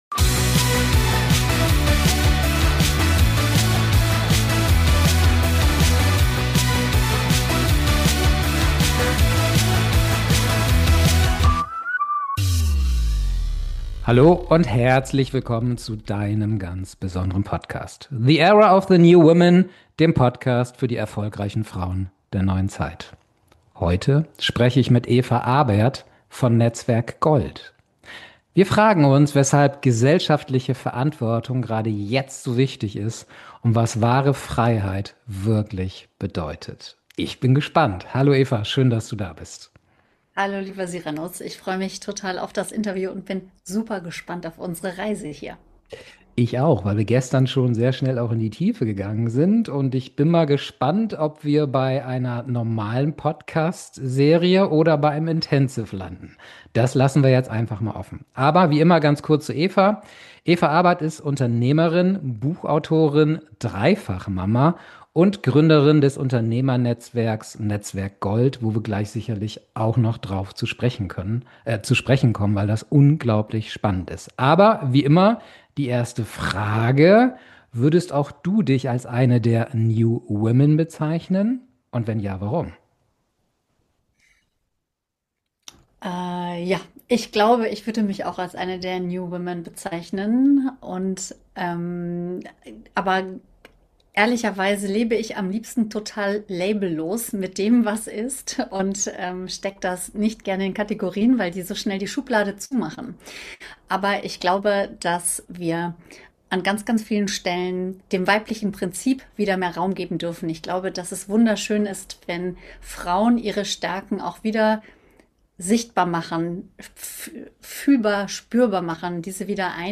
Es ist ein Gespräch, das sehr schnell in die Tiefe geführt hat.